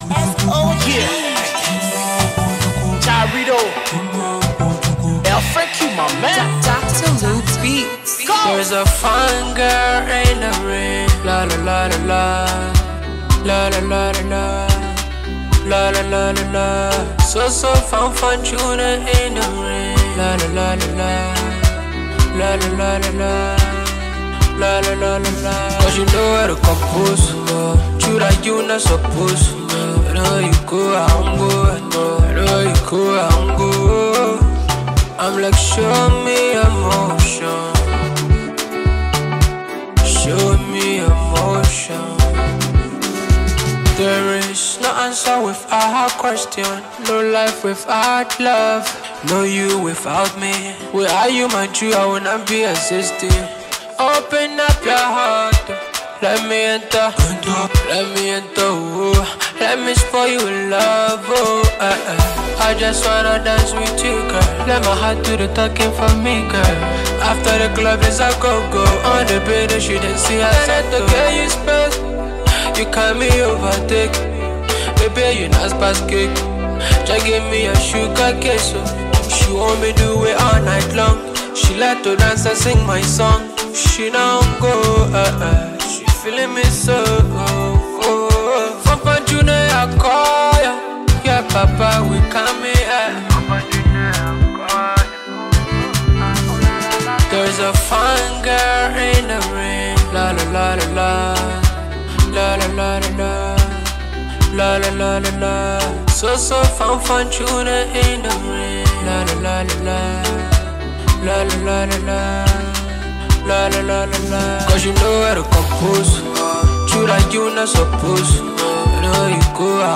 / Afrobeats/Afro-Pop, Colloquial / By
Liberian duo